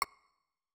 Sound / Effects / UI